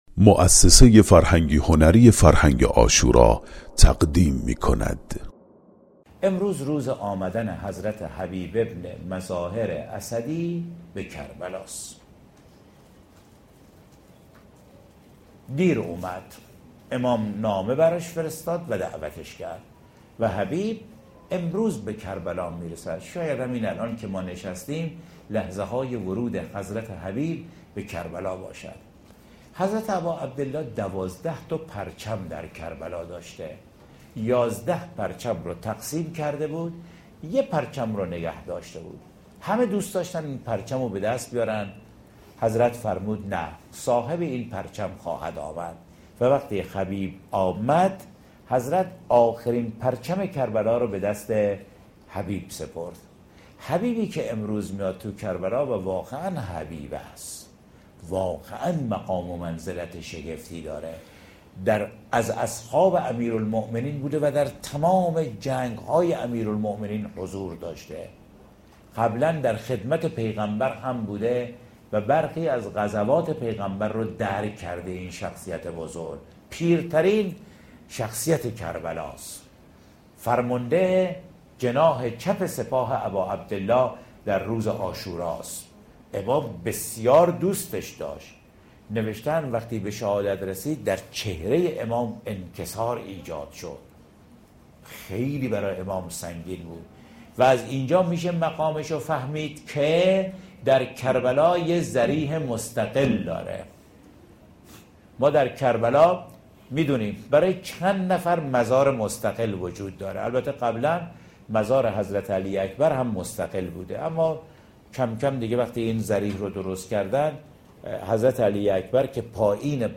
در ادامه قطعه صوتی مربوط به روز ششم محرم را می‌شنوید.